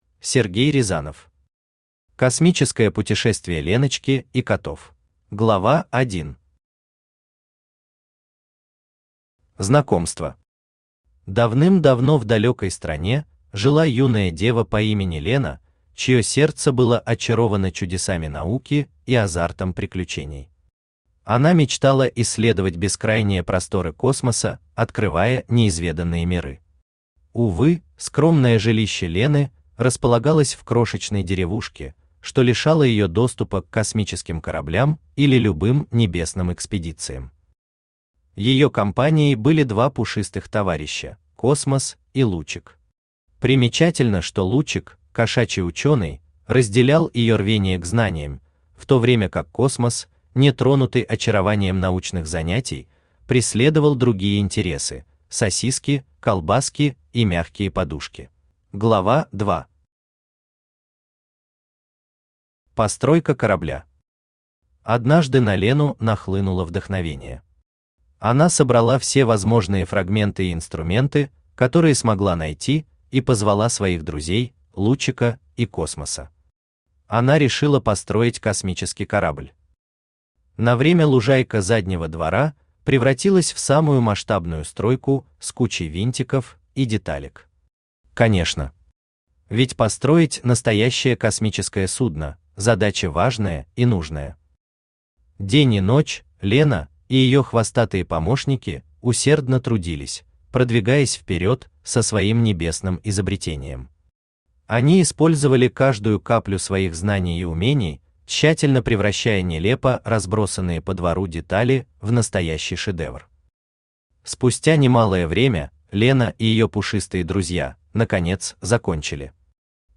Aудиокнига Космическое путешествие Леночки и котов Автор Сергей Рязанов Читает аудиокнигу Авточтец ЛитРес.